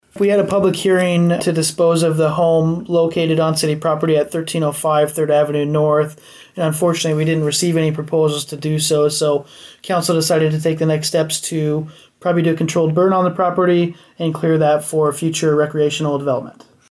City Administrator comments on Humboldt projects – 97.7 The Bolt
Humboldt, IA -At last week’s Humboldt City Council meeting, there were a couple action items on the agenda, here is City Administrator Cole Bockelmann on the updates.